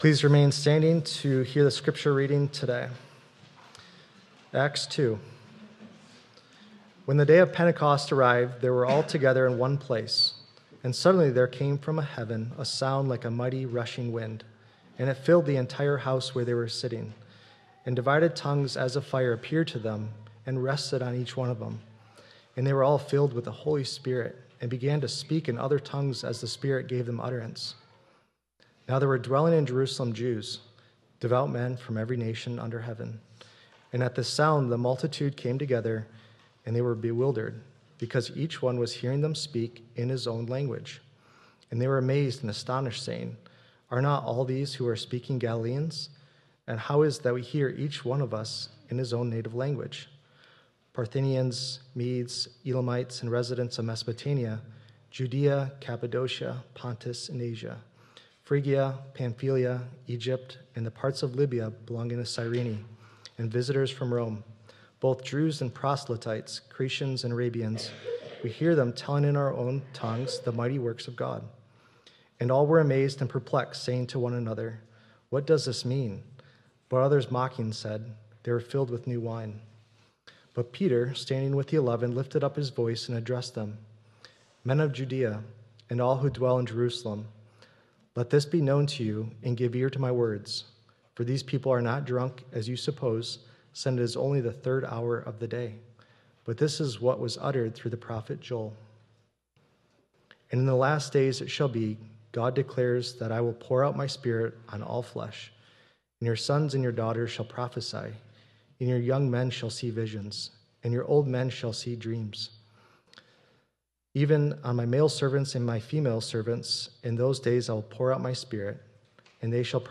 2.8.26 Sermon.m4a